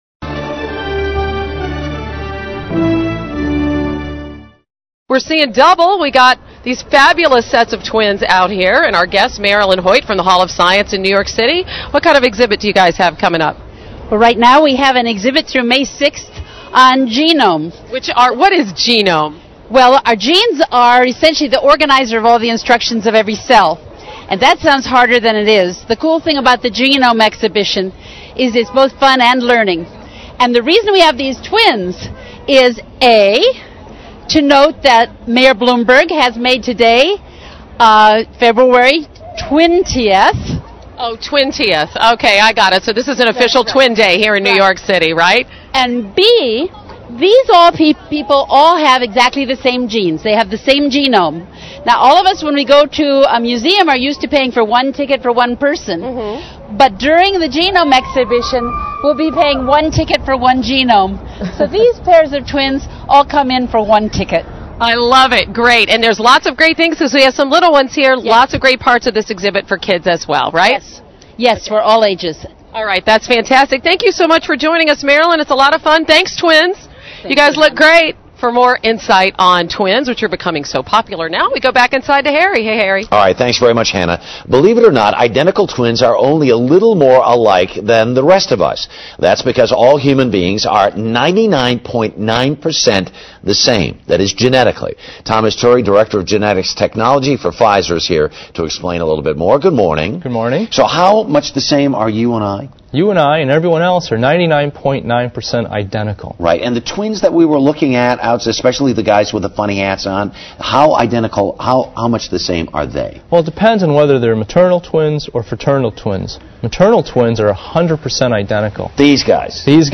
访谈录 Interview 2007-02-24&26, 双胞胎聚会 听力文件下载—在线英语听力室